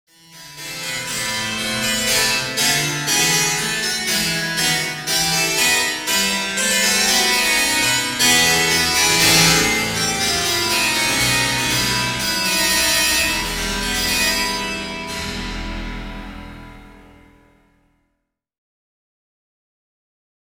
Here is a Harpsichord. It’s a keyboard instrument from the Renaissance and Baroque period of the 1700-1800’s. It was very fashionable in its day.
Harpsichord.mp3